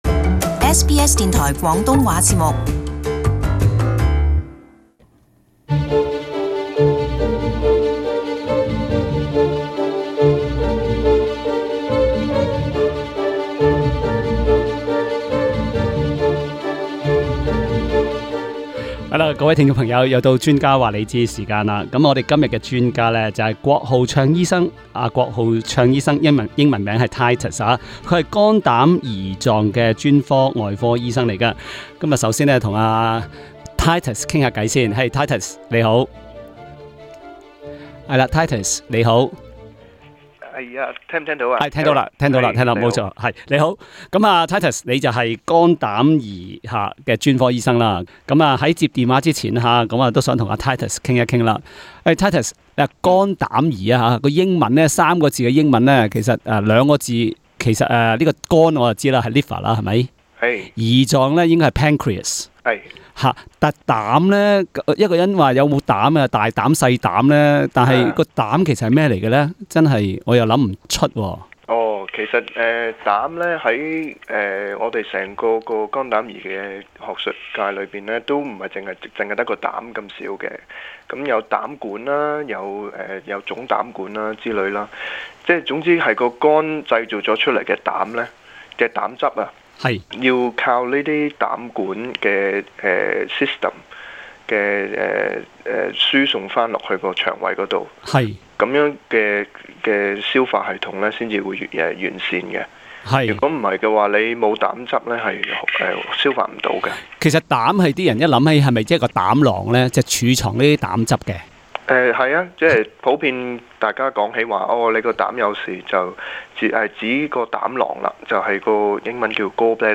【專家話你知】肝膽胰外科專家解答聽衆提問